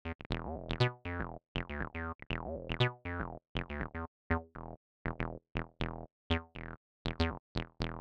8-3_Randomize_TB-303_Example.mp3